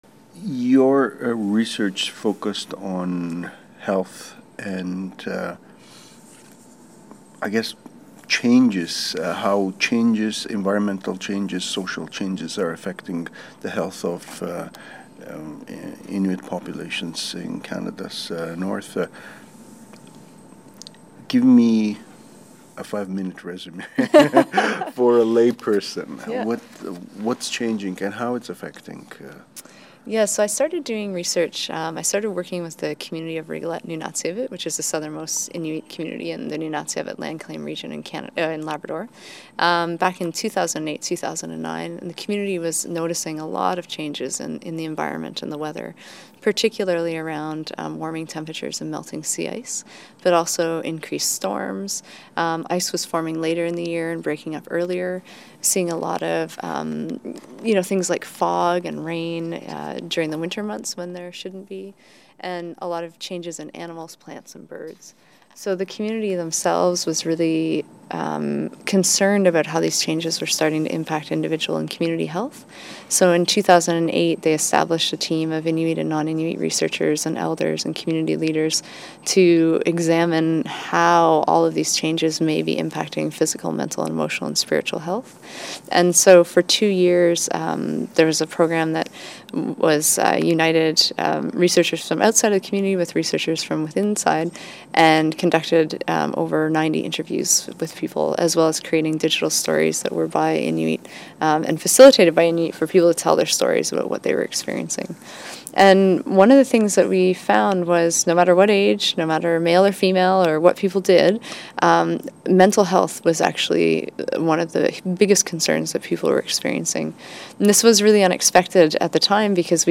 Feature interview